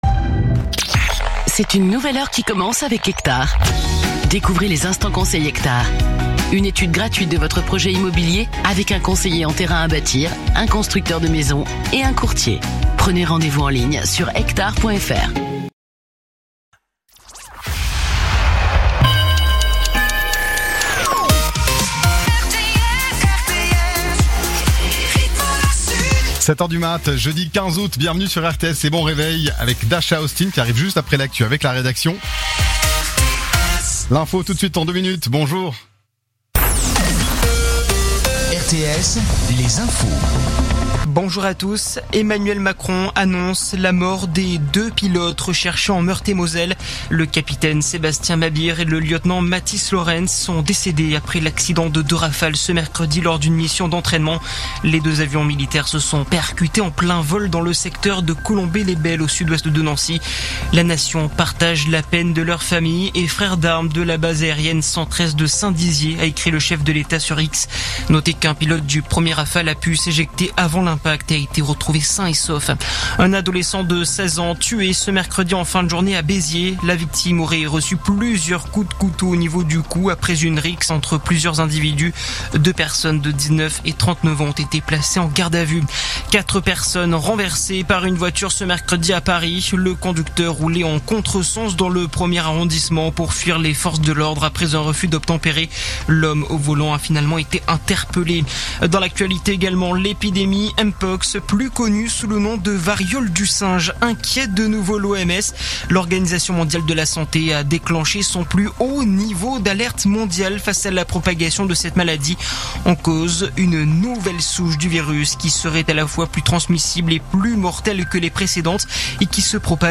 info_nimes_111.mp3